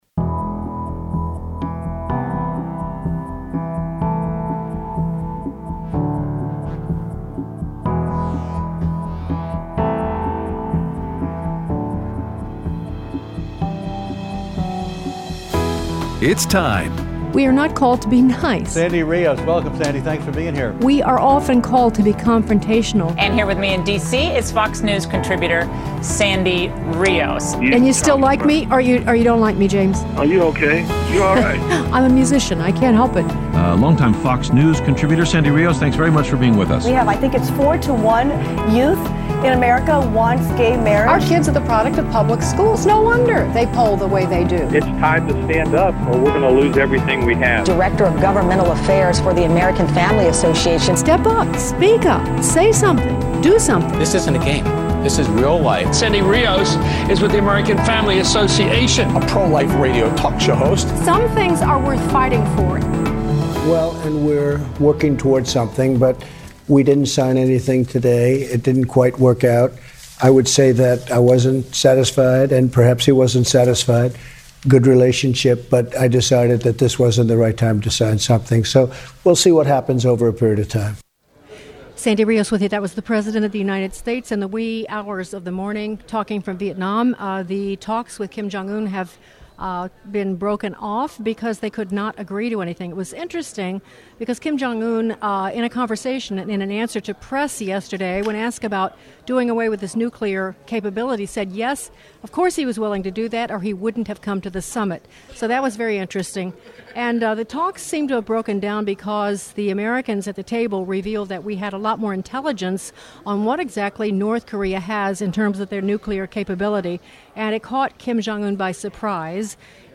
Live From CPAC 2019
Aired Thursday 2/28/19 on AFR 7:05AM - 8:00AM CST